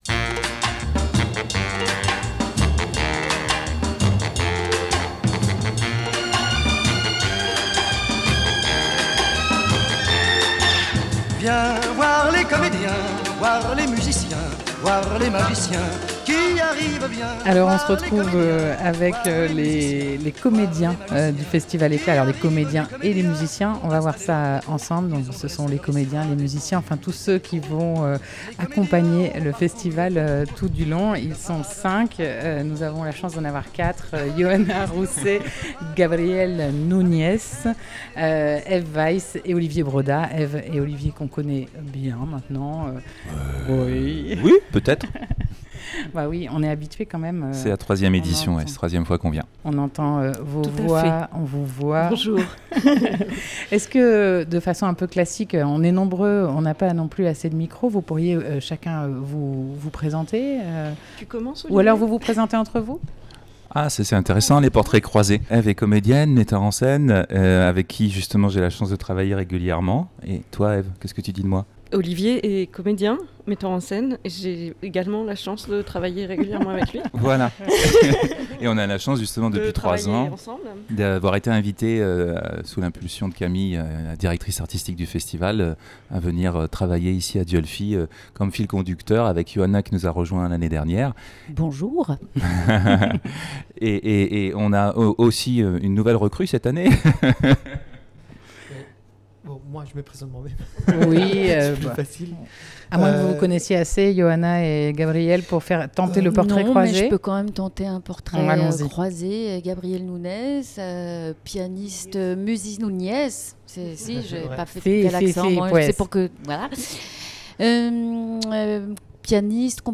14 septembre 2020 10:53 | Interview